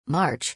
• March سومین ماه میلادی و به شکل /mɑːrt͡ʃˈ/ تلفظ می‌شود.